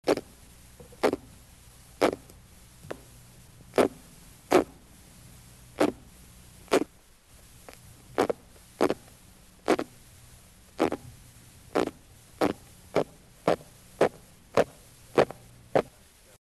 Type of sound produced grunts
Sound mechanism pharyngeal teeth stridulation amplified by adjacent swim bladder (similar to Haemulon album) Behavioural context under duress (manual stimulation), easily induced sound production
Remark recordings of two specimens